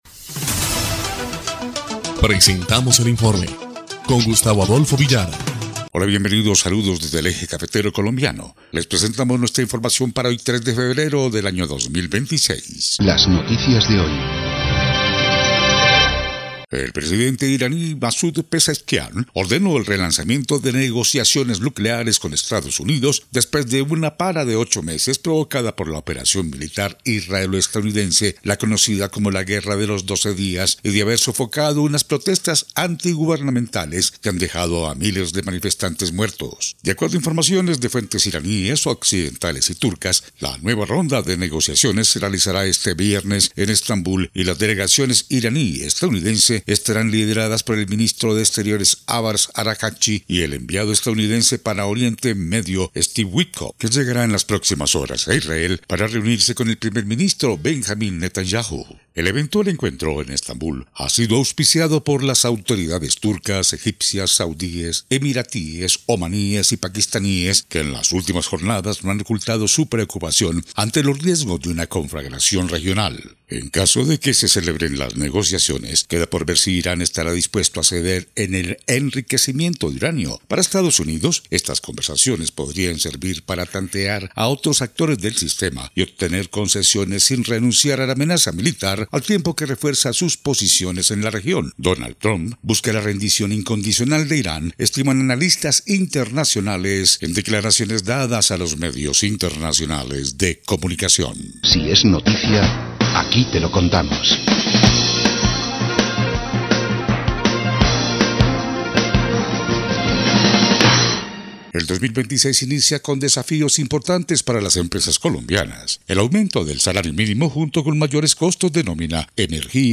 EL INFORME 2° Clip de Noticias del 3 de febrero de 2026